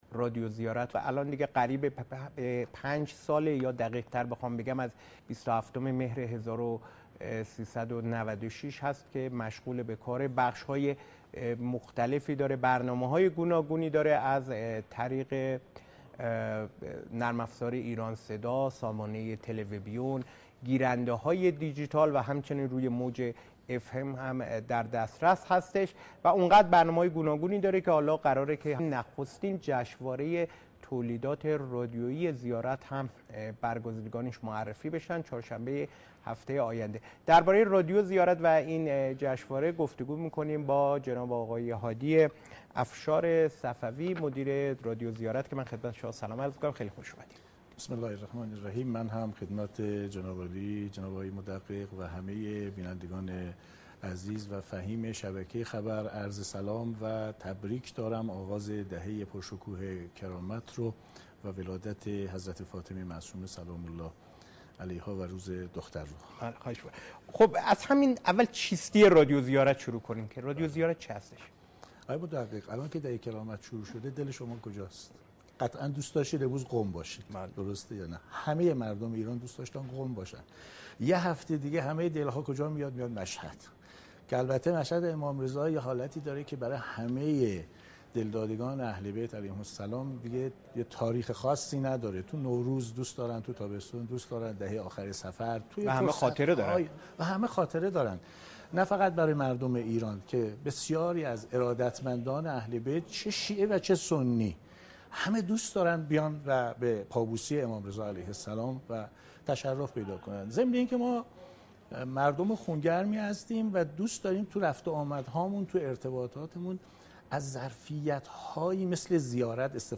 در گفتگوی با شبکه خبر